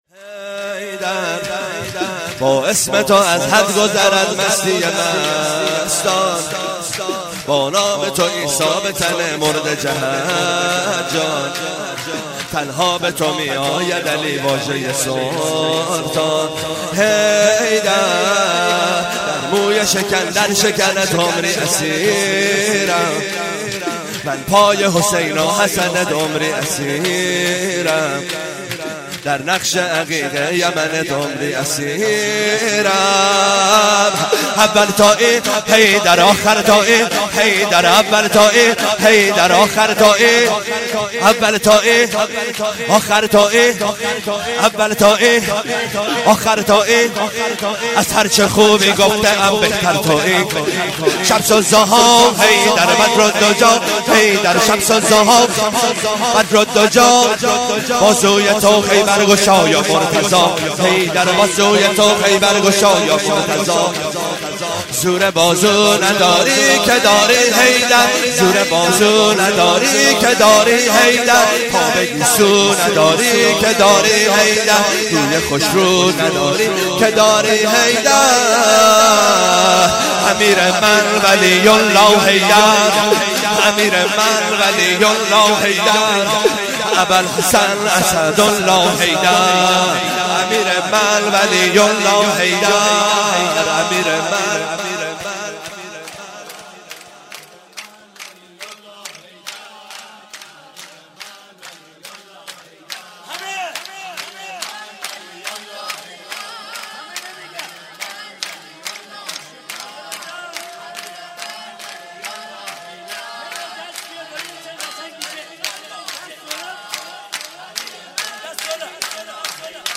ولادت حضرت امام حسن عسکری-1دیماه1396
شور زیبا